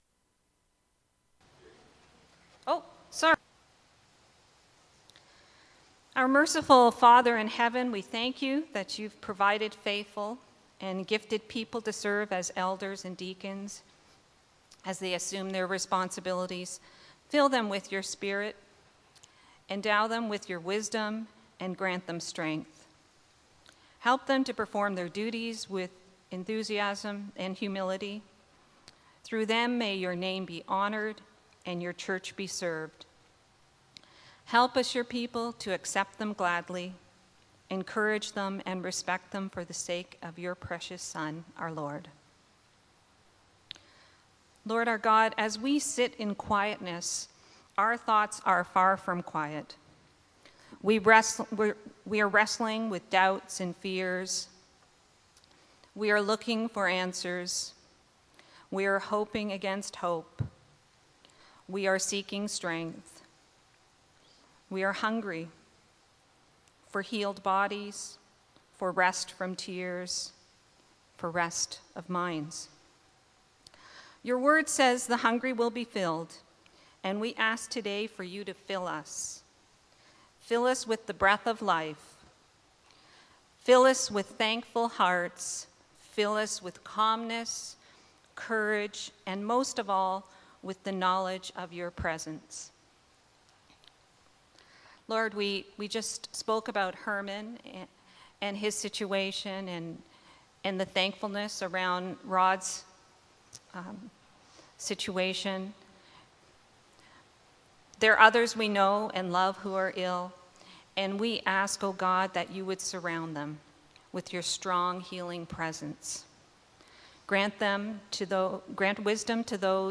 Sermons | Fellowship Christian Reformed Church of Toronto